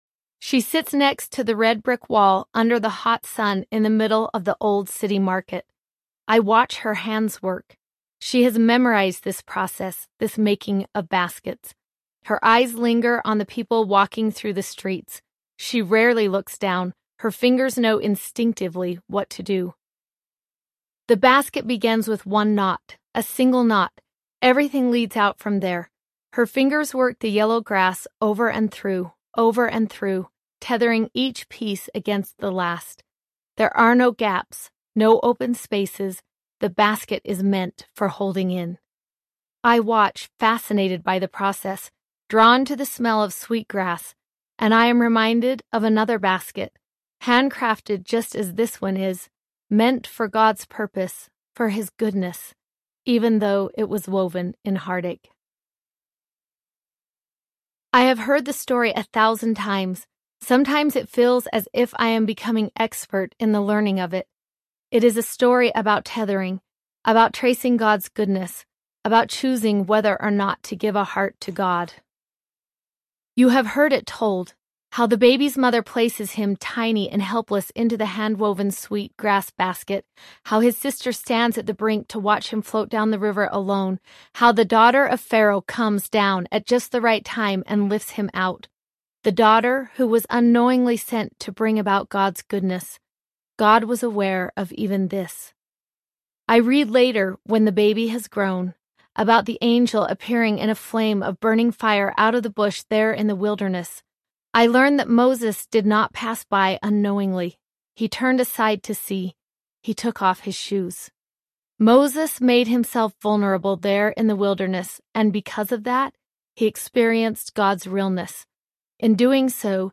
Even This Audiobook